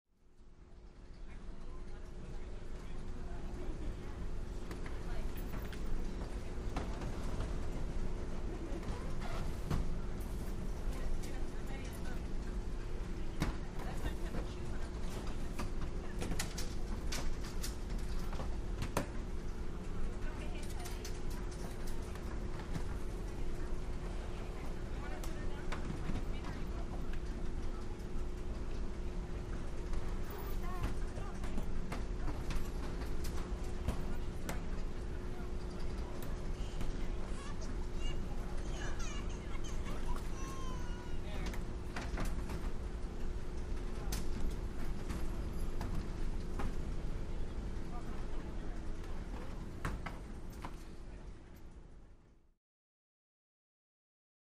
Baggage Claim Area, Belt Motion, Luggage Impacts And Mixed Walla With Baby, Close Point of View